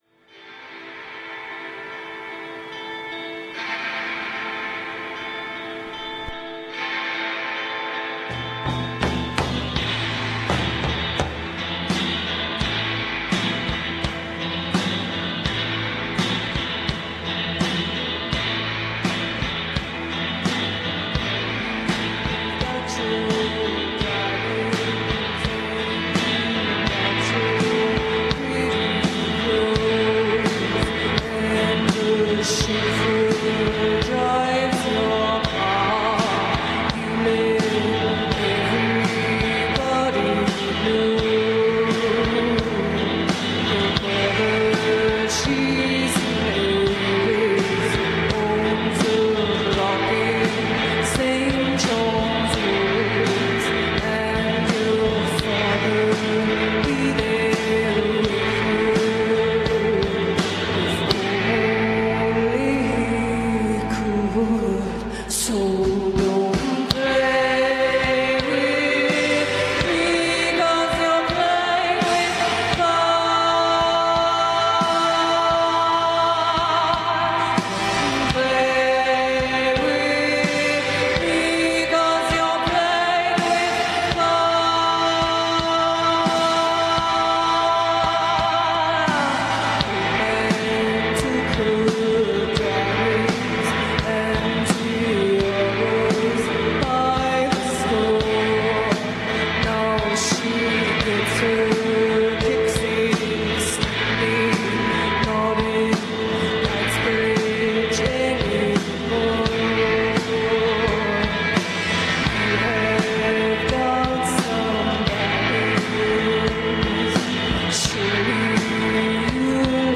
recorded May 29, 2010 at Primavera Sound Festival, Barcelona